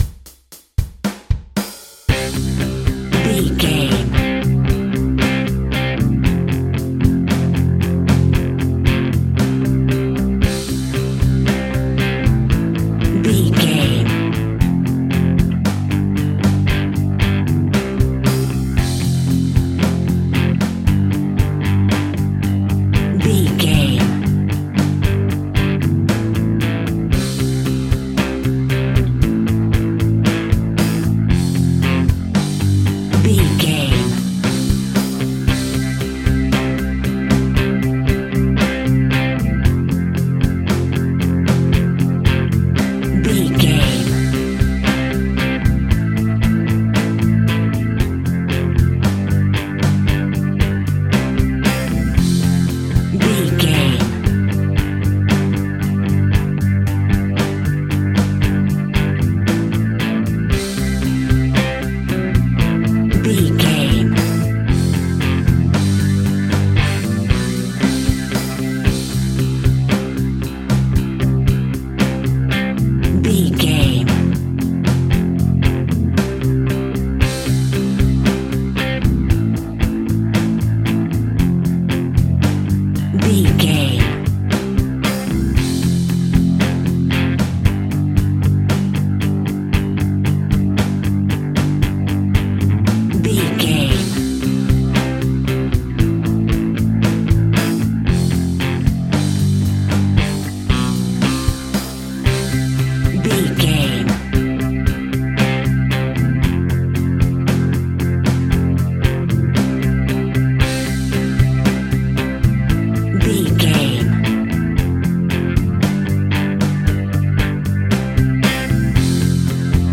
Rocking in Half Time.
Epic / Action
Fast paced
Ionian/Major
heavy metal
heavy rock
blues rock
distortion
hard rock
Instrumental rock
drums
bass guitar
electric guitar
piano
hammond organ